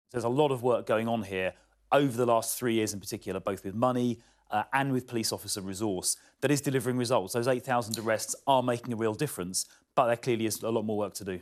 Croydon South MP Chris Philp speaking about Young People